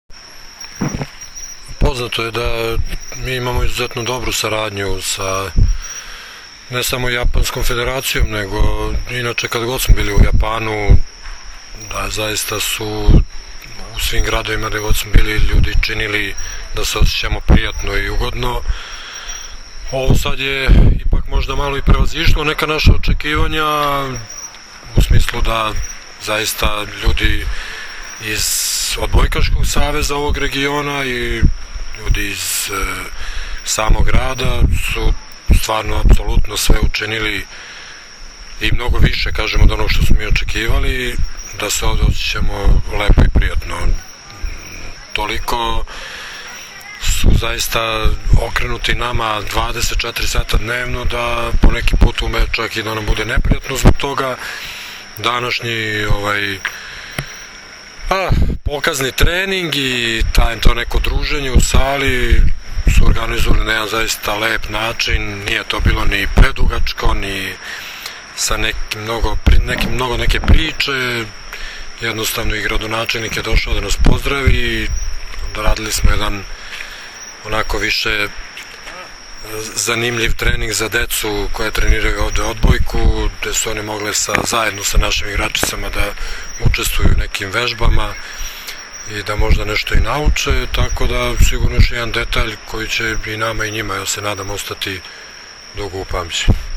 IZJAVA ZORANA TERZIĆA